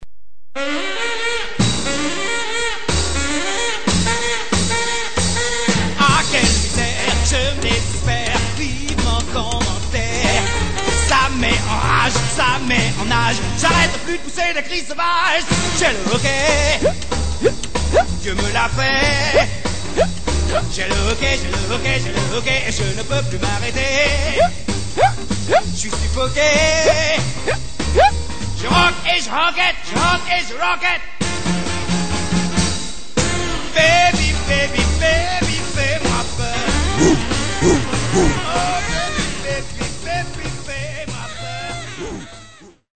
Rock and Roll à la française...